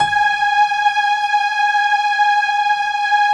SI1 PIANO0CL.wav